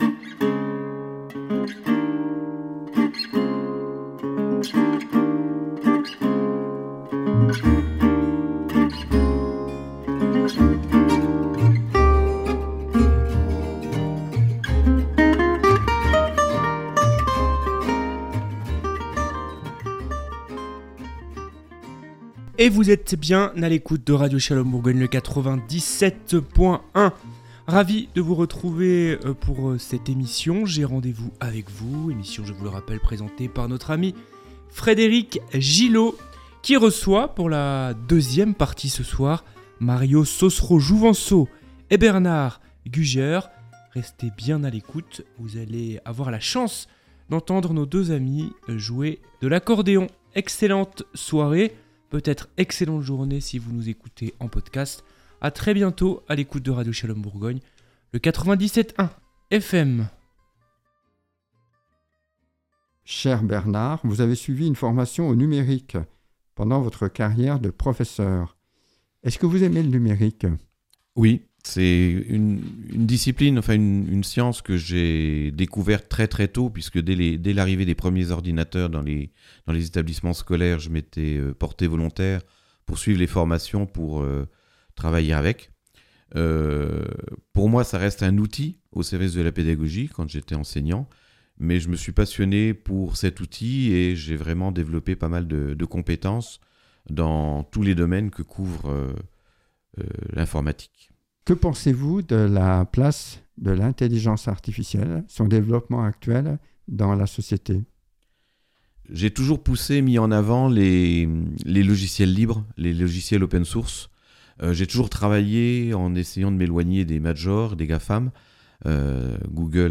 Pour cenouveau numéro de J’ai rdv avec vous, j’ai choisi deux invités au parcours aussipassionnant que diversifié.